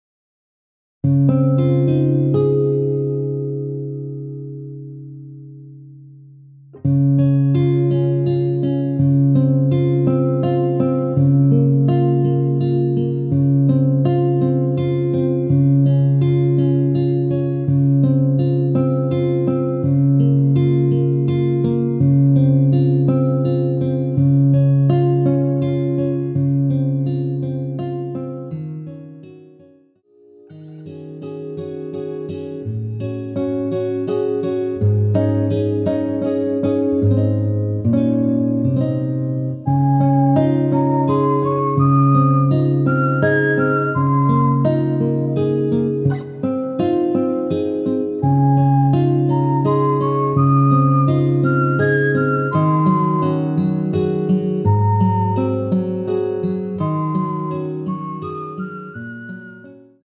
대부분의 여성분들이 부르실수 있는 키로 제작 하였습니다.
C#
앞부분30초, 뒷부분30초씩 편집해서 올려 드리고 있습니다.
중간에 음이 끈어지고 다시 나오는 이유는